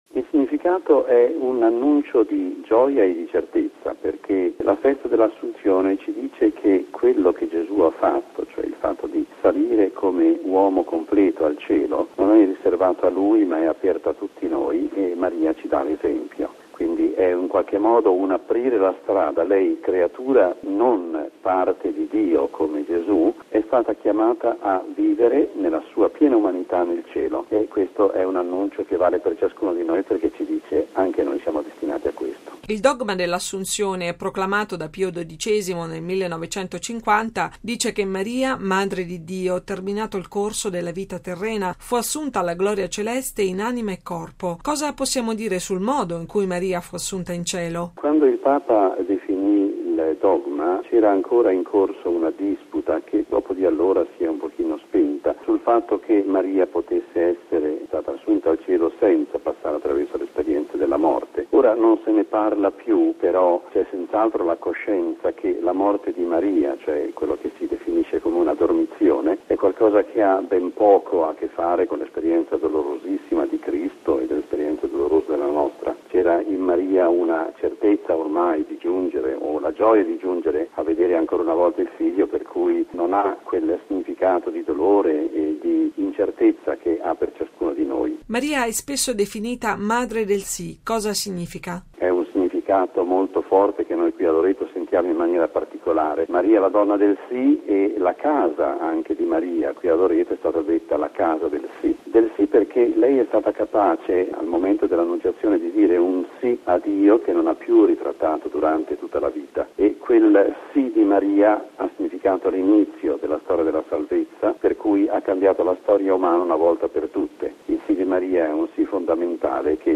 ◊   Sul significato e l’importanza della Solennità dell’Assunzione, ascoltiamo la riflessione di mons. Giovanni Tonucci, arcivescovo prelato di Loreto e delegato pontificio per il Santuario Lauretano.